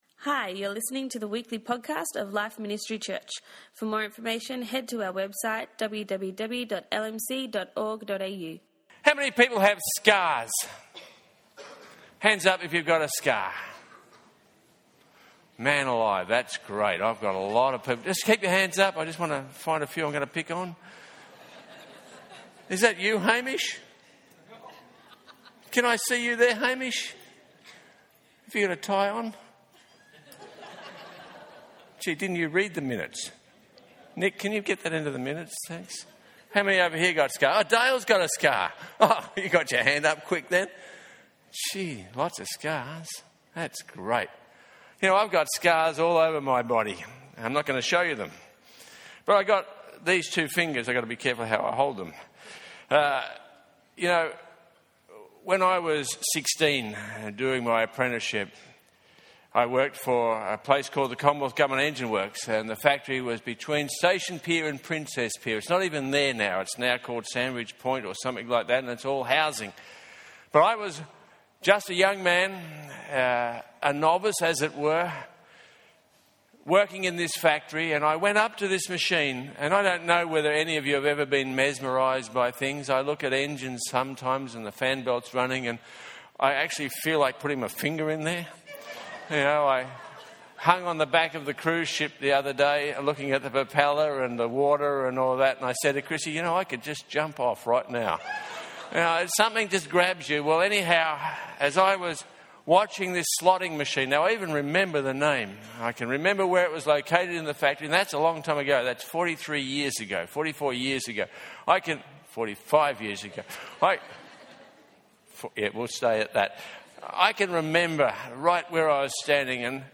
download the notes This is message No.5 in the series, Re-digging the Old Wells. We all bear scars - physical, emotional and spiritual.